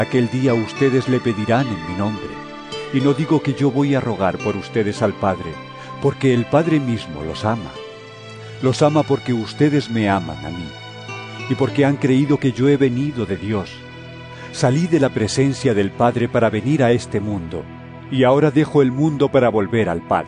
Jn 16 23-28 EVANGELIO EN AUDIO